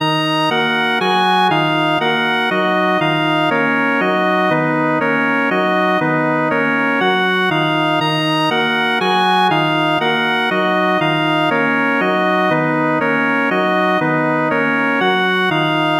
描述：um and Bass, Traq, Allright, Trap,LoFi, Lofi.
标签： 120 bpm Trap Loops Organ Loops 2.69 MB wav Key : E FL Studio
声道立体声